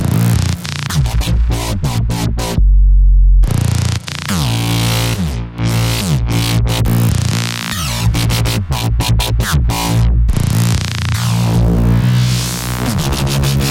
黄蜂摇摆鼓
描述：都是在两个循环中的谐波之一
Tag: 140 bpm Dubstep Loops Bass Wobble Loops 2.31 MB wav Key : Unknown